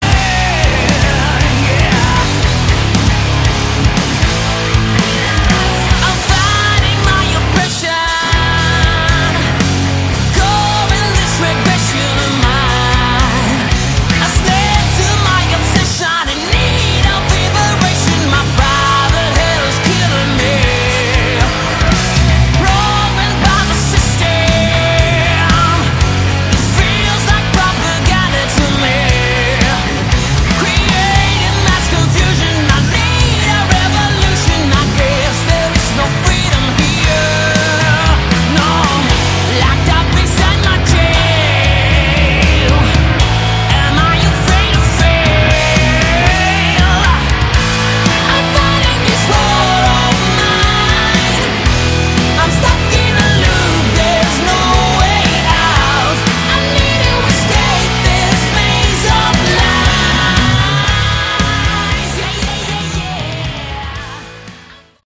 Category: Hard Rock
lead vocals
lead guitars
rhythm guitar
bass
drums